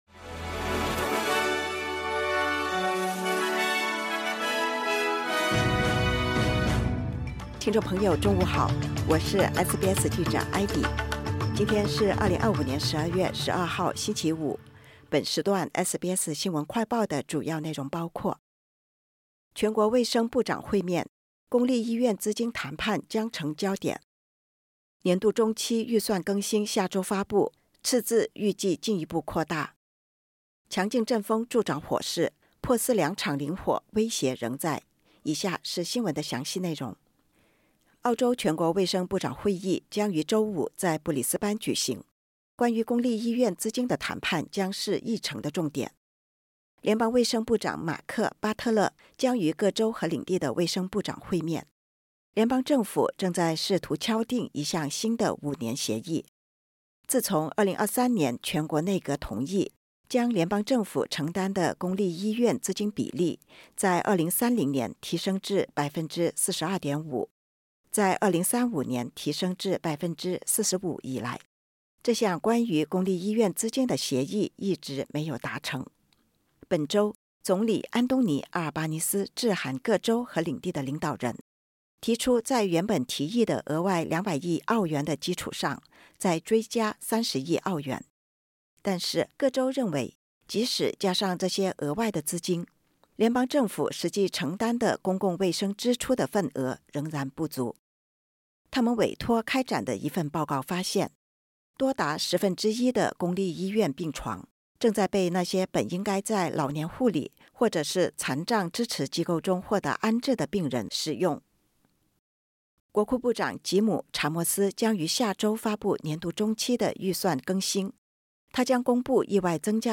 收听完整播客系列 SBS 新闻快报 澳洲全国卫生部长会面 公立医院资金谈判将成焦点 据澳联社报道，周五（12月12日），于布里斯班召开的澳大利亚全国卫生部长会议上，关于公立医院资金的谈判将是议程的重点。